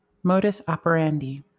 ÄäntäminenUS:
• IPA: /ˈmoʊ.dɘs ˌɑp.əɹ.ɛn.daɪ/